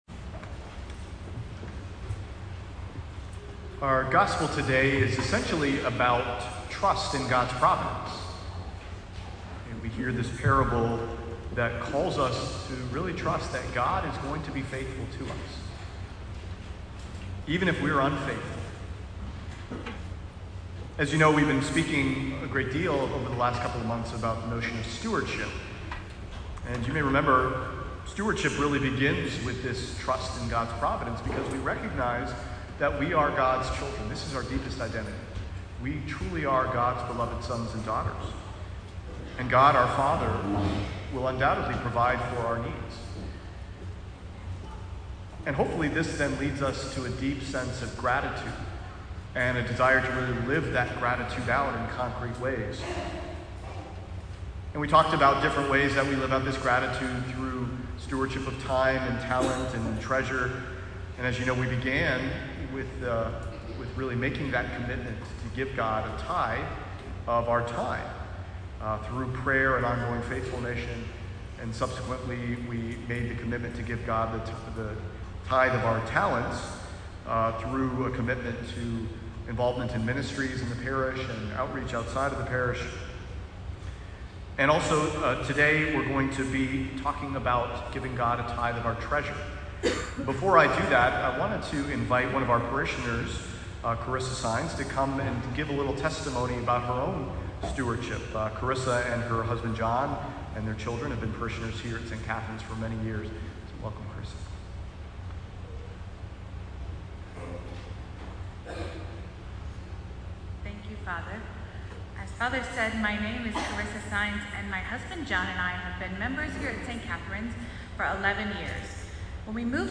Two of our parishioners offered testimony about their personal path towards stewardship at St. Catherine's. If you were unable to attend Mass at St. Catherine's this weekend, I invite you to listen to this recording of the testimony.